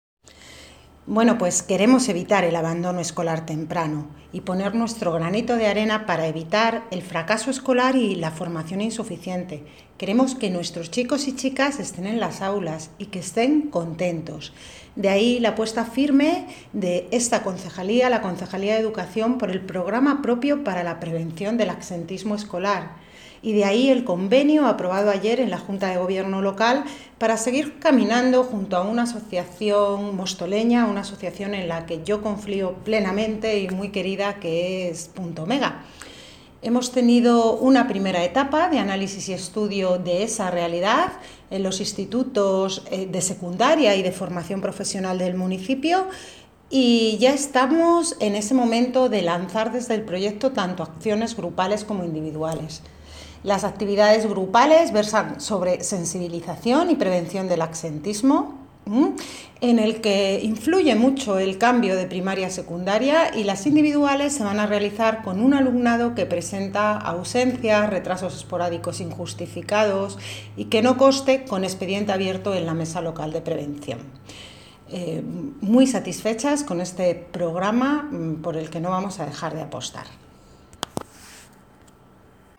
Audio - María Isabel Cruceta (Concejala de Educación) Sobre convenio punto omega
Audio - María Isabel Cruceta (Concejala de Educación) Sobre convenio punto omega.mp3